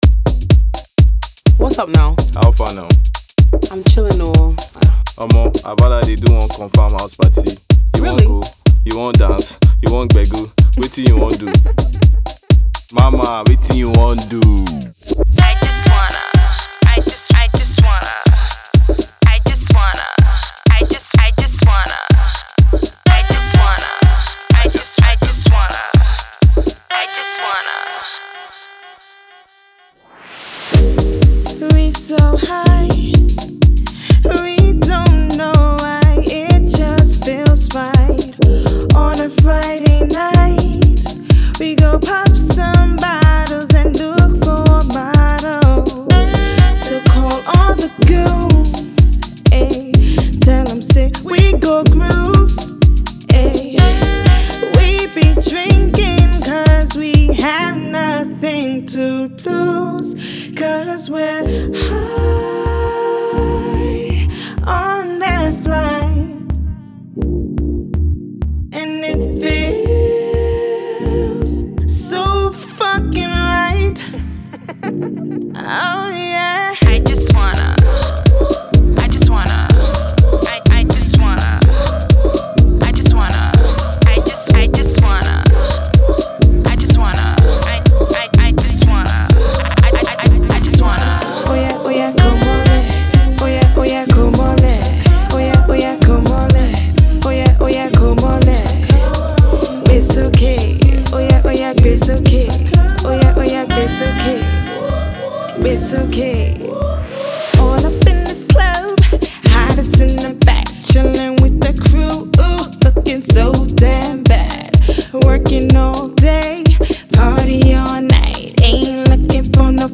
has more of a South African Afro -House feel.